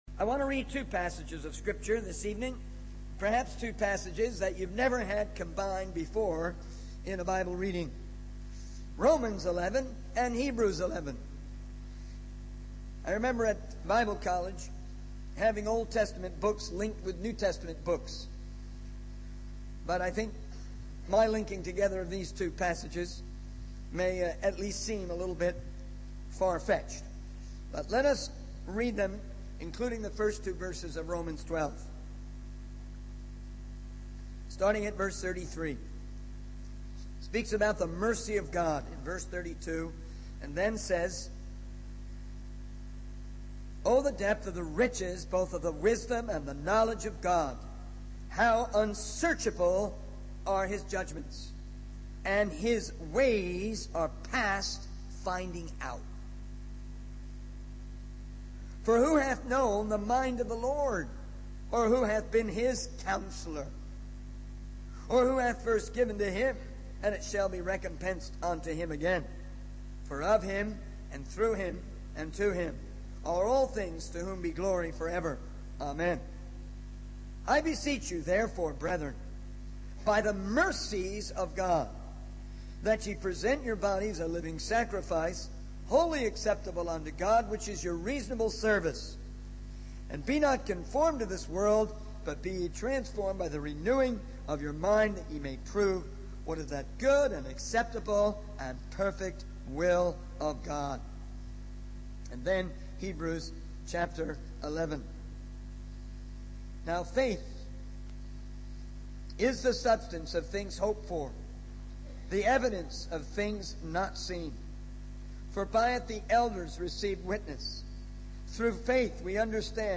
In this sermon, the speaker reflects on the importance of faith and taking steps of faith in one's life.